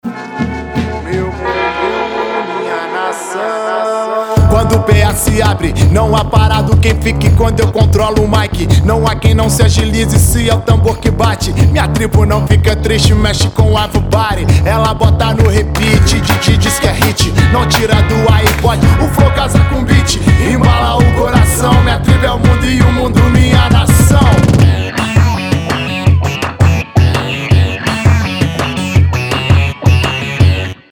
Хип-хоп
красивый мужской голос